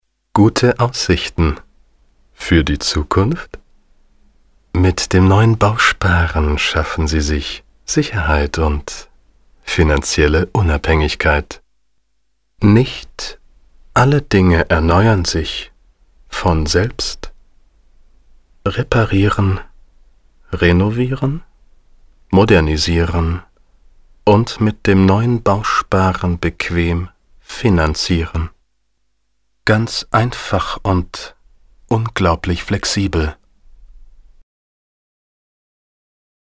deutscher Sprecher
Kein Dialekt
Sprechprobe: Sonstiges (Muttersprache):
german voice over artist